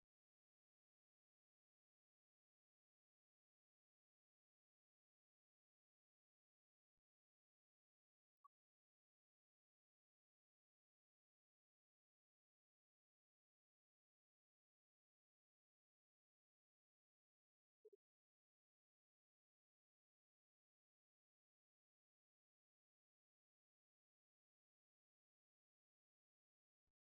musique, ensemble musical
archives sonores réenregistrées
Pièce musicale inédite